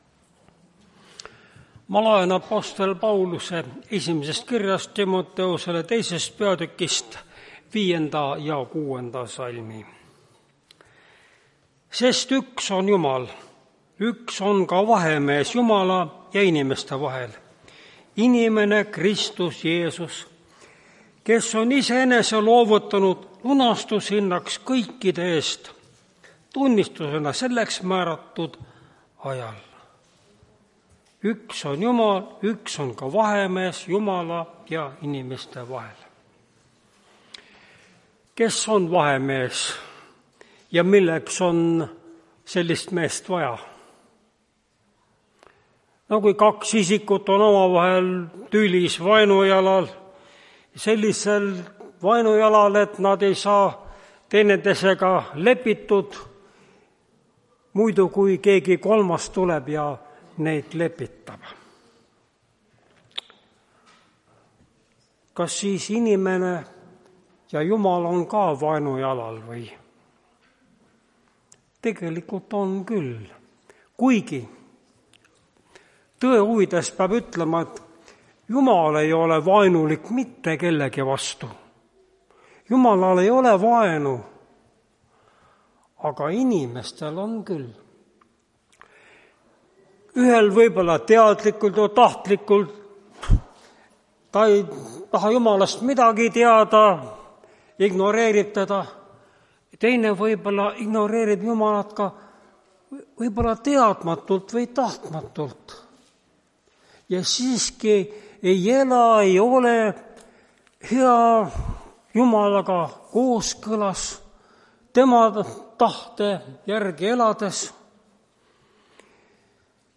Tartu adventkoguduse 31.01.2026 teenistuse jutluse helisalvestis.